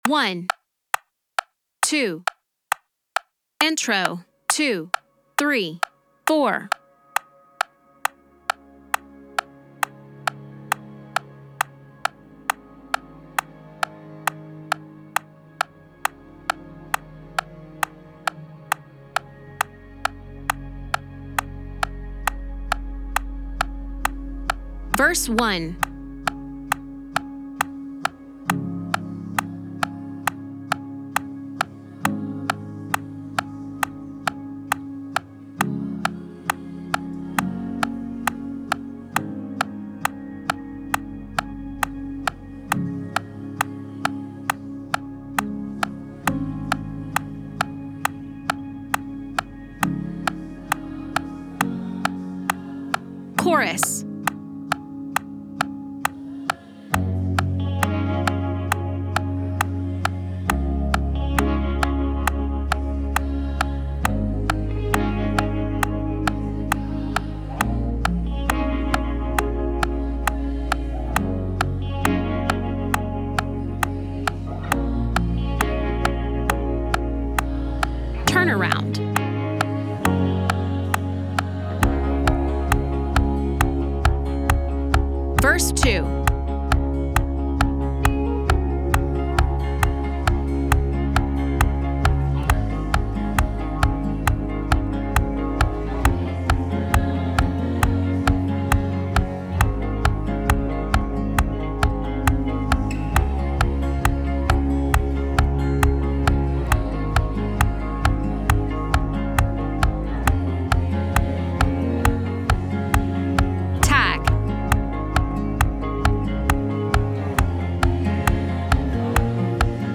Son of suffering Db - tracks and guide and click.mp3